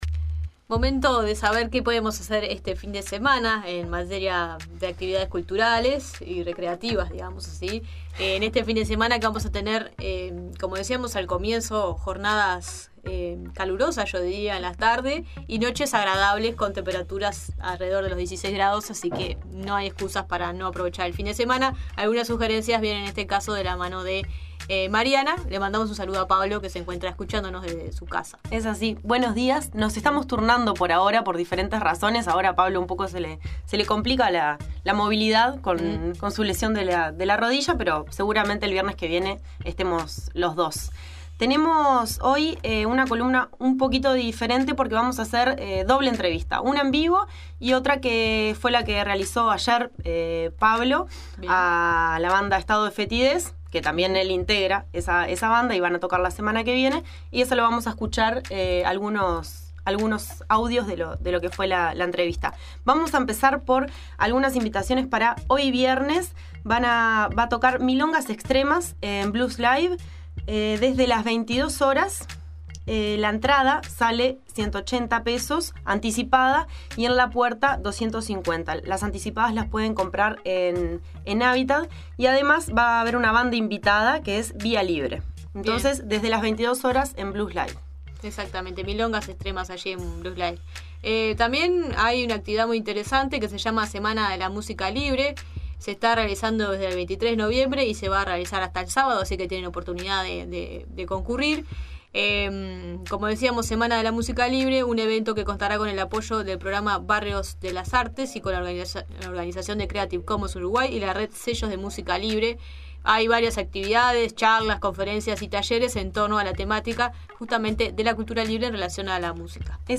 En la Cartelera Paralela de La Nueva Mañana, hablamos de la Semana de la Música Libre que se está desarrollando desde el 23 de noviembre hasta este sábado con el apoyo del programa Barrio de las Artes y con la organización de Creative Commons Uruguay y la Red de Sellos de Música Libre.. Entrevistamos